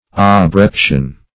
Search Result for " abreption" : The Collaborative International Dictionary of English v.0.48: Abreption \Ab*rep"tion\, n. [L. abreptus, p. p. of abripere to snatch away; ab + rapere to snatch.]